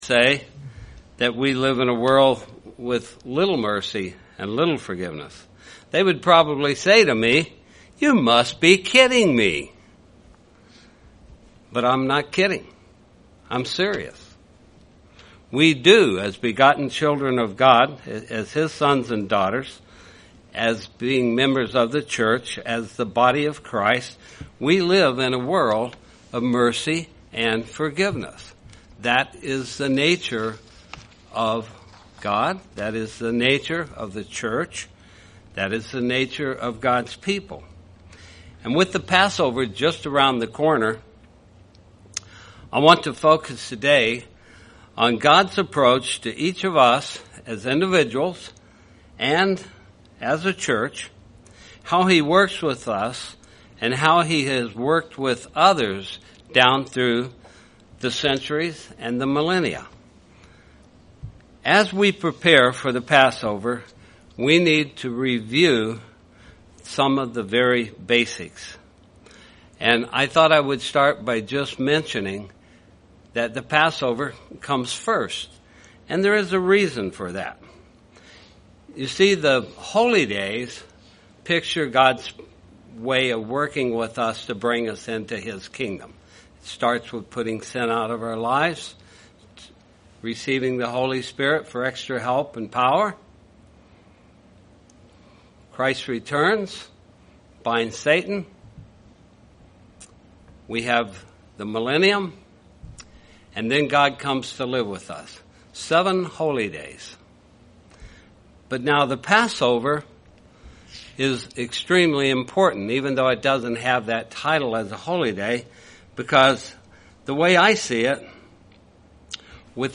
This sermon looks into God's mercy and forgiveness.